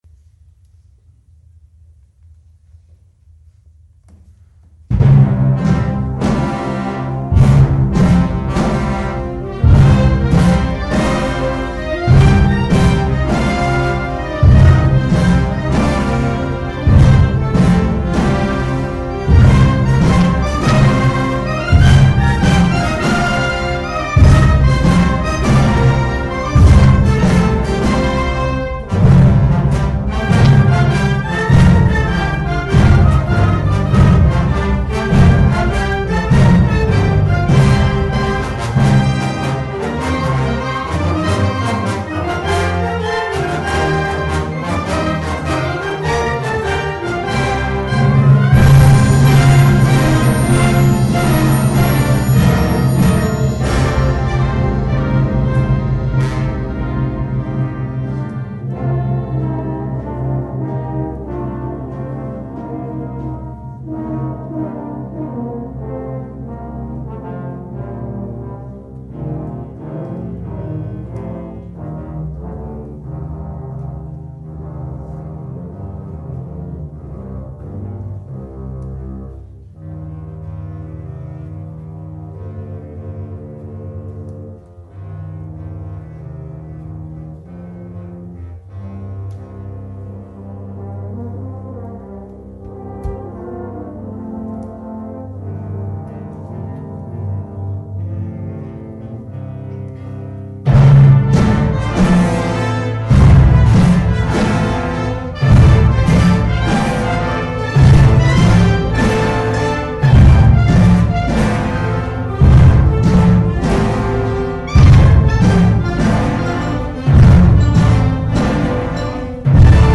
Geïnspireerd op Keltische volksmuziek, jig, doedelzak etc. ; Een zeer vrolijk slot, en een werk met een onophoudelijke beweging, eindigend met een zeer opwindende variatie-vorm op een Jigthema.
A very happy final piece, variations on a jig-like theme.
KeltischeFantasieOrkestversienagalm.mp3